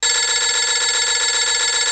Kategorien: Telefon